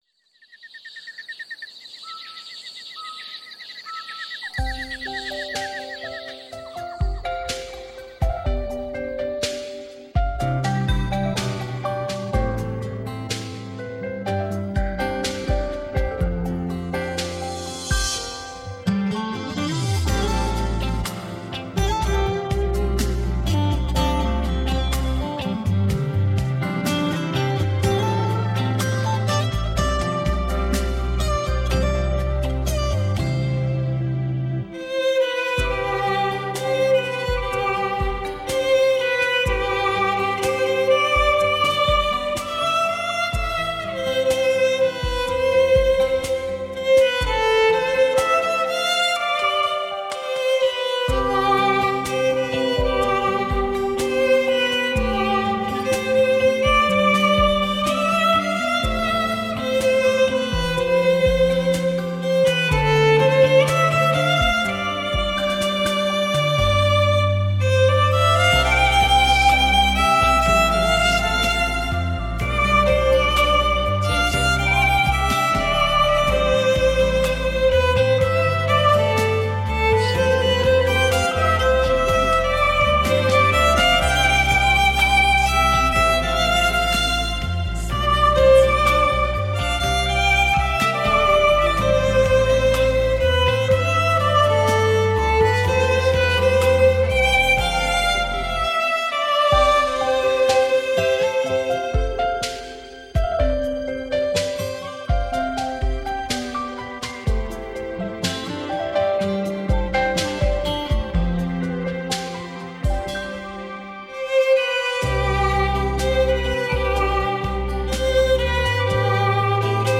休闲减压音乐系列 小提琴
24bit-192KHz母带数位制作
温馨柔和的醉人旋律 扣人心弦的小提琴音
“唱”出动人的乐章 细腻丰富的感情表露无遗
小提琴被誉为“乐器之后”，音色温暖，纯净甜美、极近女声。